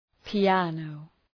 {pi:’ænəʋ}